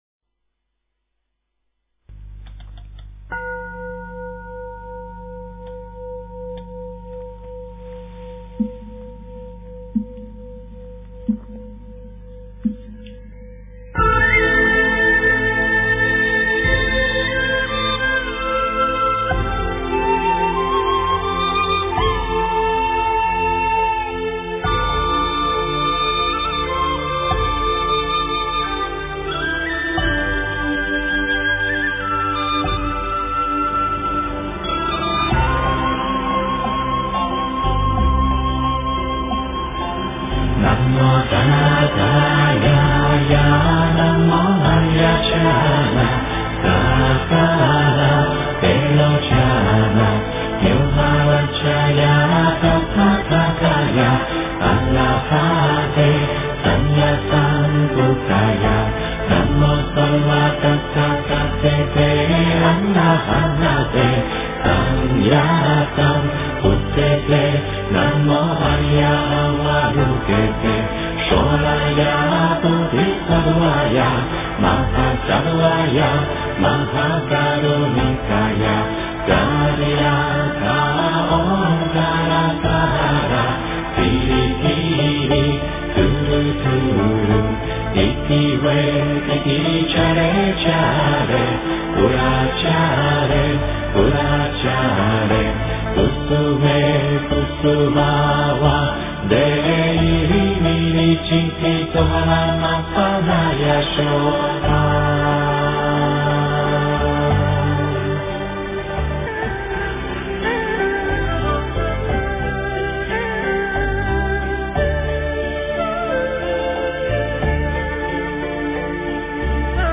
诵经
佛音 诵经 佛教音乐 返回列表 上一篇： 心经(钢琴版